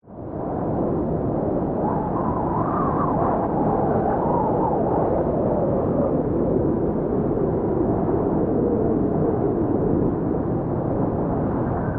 Howling Wind
Howling Wind is a free horror sound effect available for download in MP3 format.
092_howling_wind.mp3